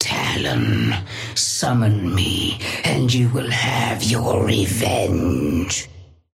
Sapphire Flame voice line - Talon, summon me and you will have your revenge.
Patron_female_ally_orion_start_06.mp3